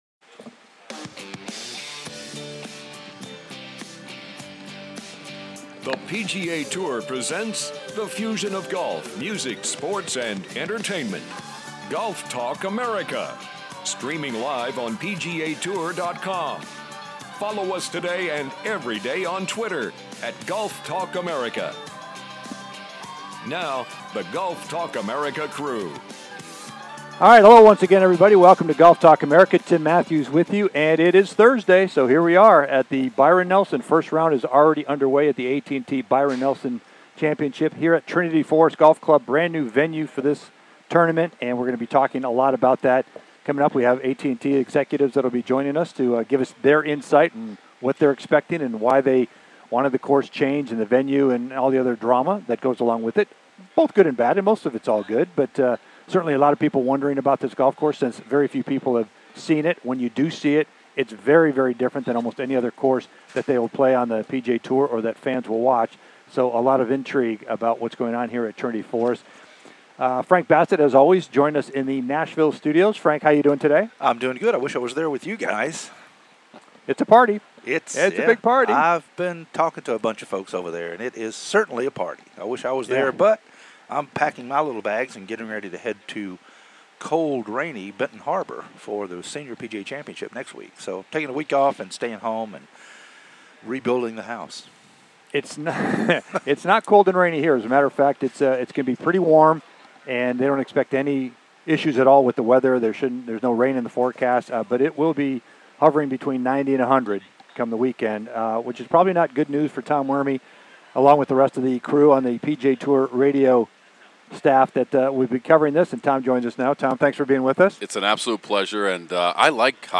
"LIVE" From The AT&T Byron Nelson